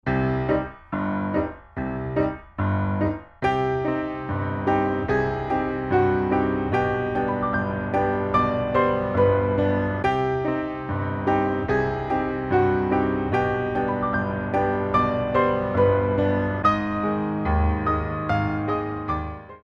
Coda
2/4 - 128 with repeat